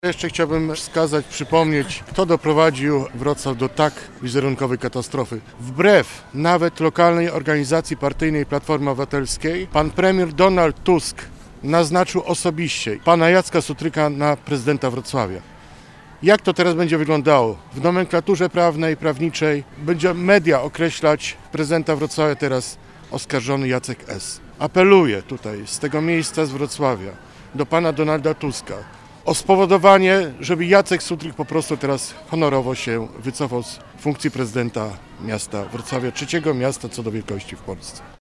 Sławomir Śmigielski, radny z klubu PiS dodaje, że jego zdaniem za wizerunkową katastrofę Wrocławia odpowiedzialny jest premier Donald Tusk.